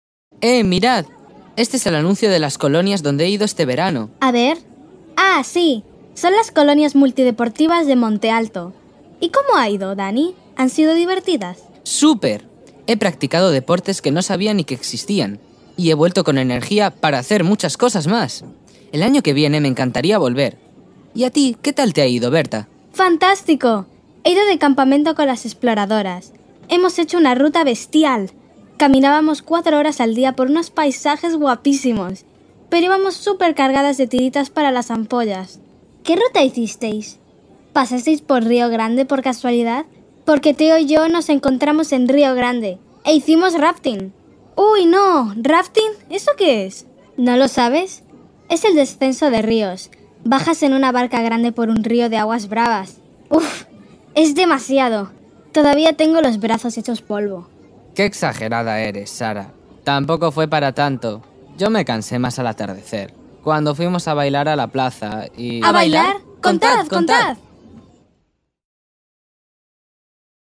ESCUCHAMOS Y COMPRENDEMOS UNA CONVERSACIÓN